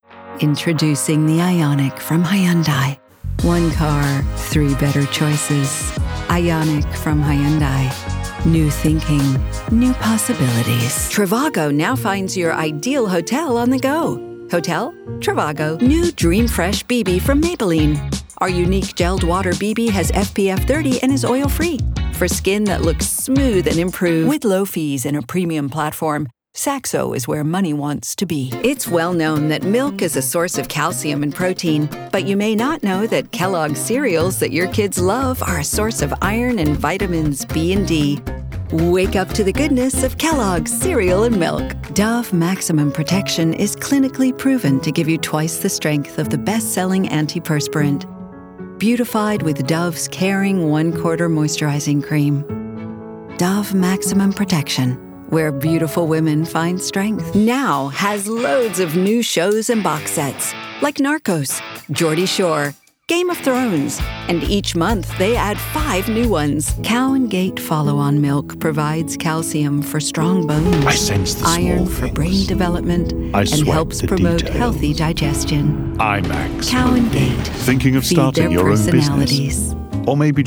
Commercial Showreel